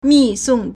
密送 mìsòng
mi4song4.mp3